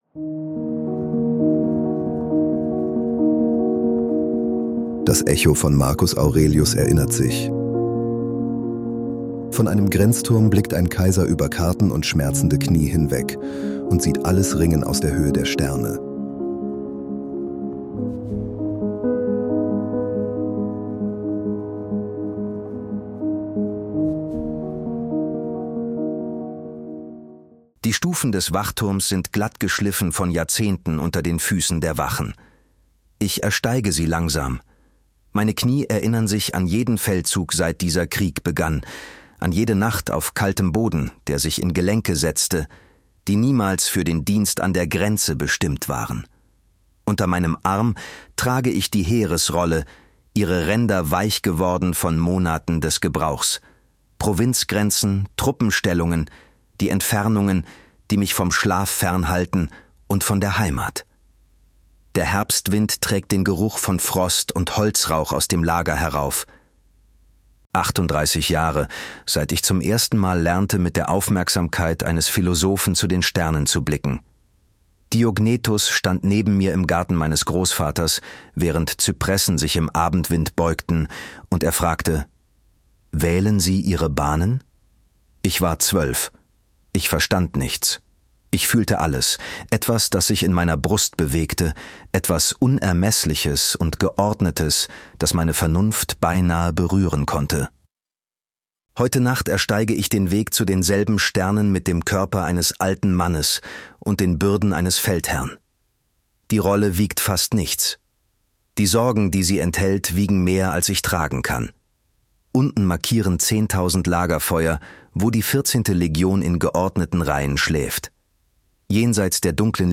Wir nutzen synthetische Stimmen, damit diese Geschichten kostenlos bleiben, ohne Werbung — und dich in mehreren Sprachen erreichen.